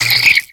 Cri de Posipi dans Pokémon X et Y.